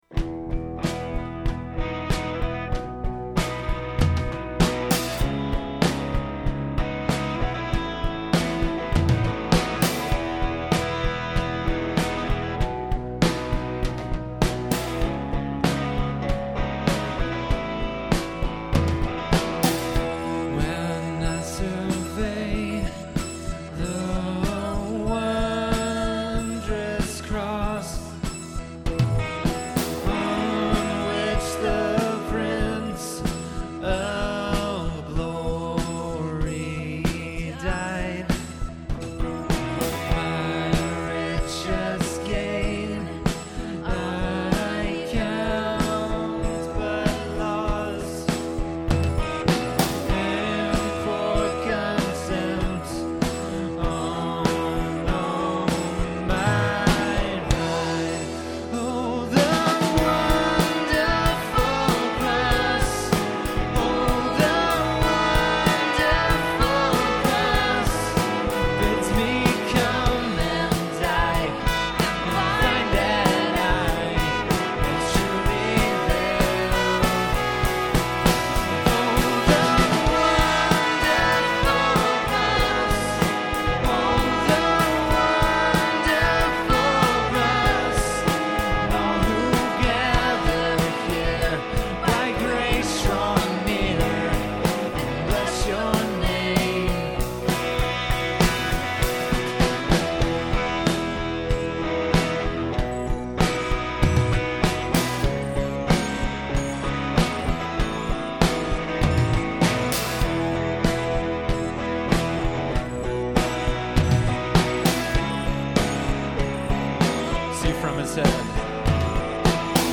Performed live at Terra Nova - Troy on 4/19/09.